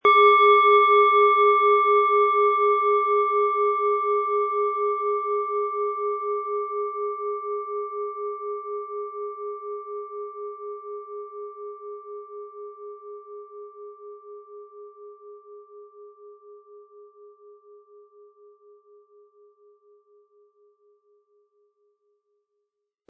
Planetenton 1
Im Sound-Player - Jetzt reinhören hören Sie den Original-Ton dieser Schale.
SchalenformBihar
MaterialBronze